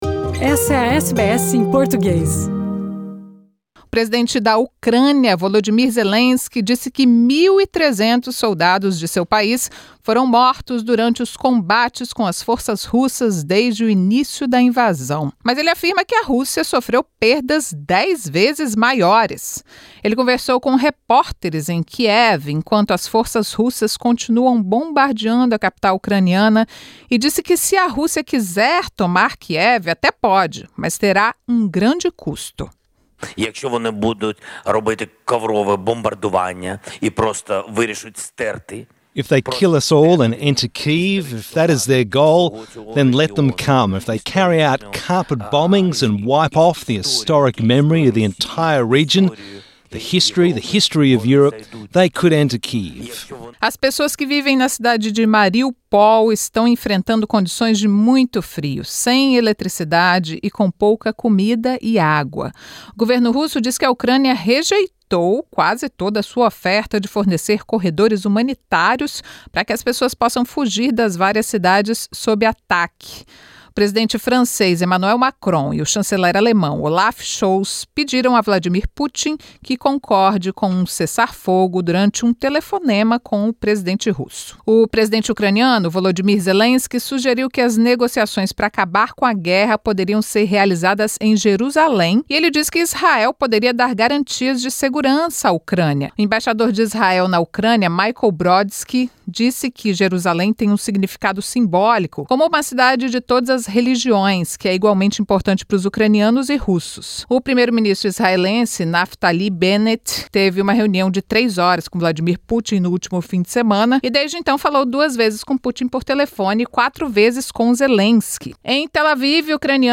Presidente ucraniano diz que a Rússia pode até tomar Kiev, mas terá um grande custo, e sugere que negociações para acabar com a guerra sejam realizadas em Jerusalém. 13 refugiados teriam sido libertados da detenção de imigração na Austrália desde sexta-feira. Petrobras e Acelen terão que explicar a Ministério da Justiça brasileiro os recentes aumentos da gasolina e do diesel. As notícias da Austrália e do mundo da Rádio SBS para este domingo.